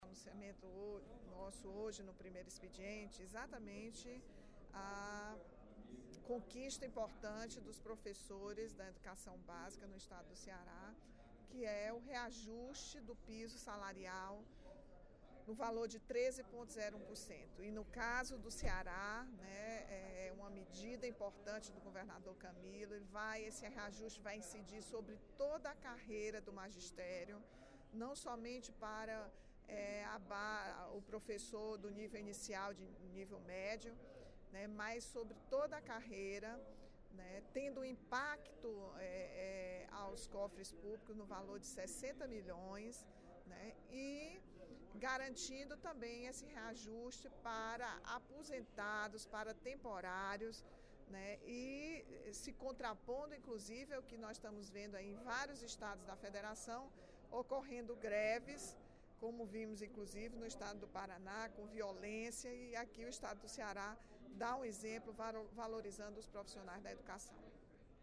A deputada Rachel Marques (PT) destacou, durante o primeiro expediente da sessão plenária desta quarta-feira (27/05), a mensagem que o governador Camilo Santana vai apresentar à Assembleia nesta quinta-feira (28/05).